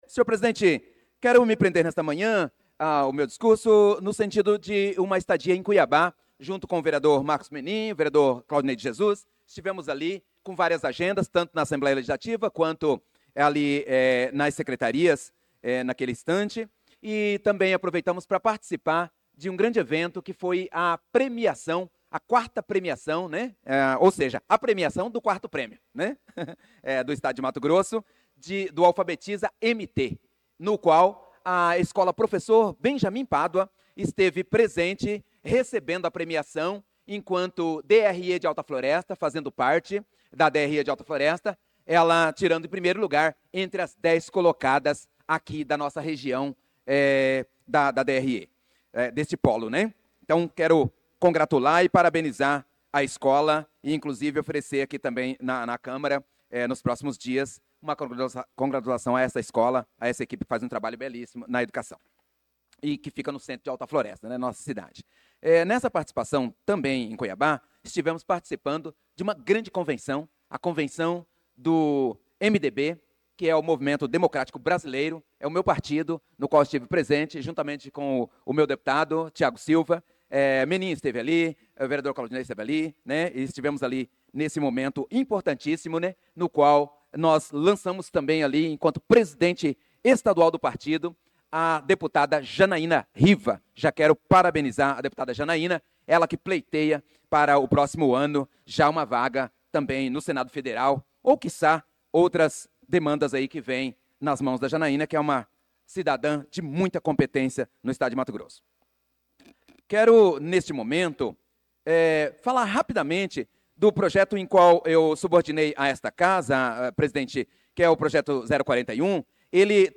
Pronunciamento do vereador Prof. Nilson na Sessão Ordinária do dia 25/08/2025.